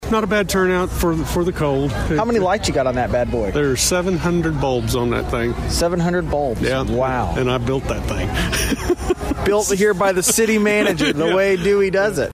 Community Gathers for 8th Annual Dewey Christmas Tree Lighting
The Dewey High School Holiday Choir filled the night with festive carols, their voices carrying across the park as children lined up to share wishes and snap photos with Santa. When the time came, Santa led the countdown and lit the tree, prompting cheers as the park erupted in color and sparkle.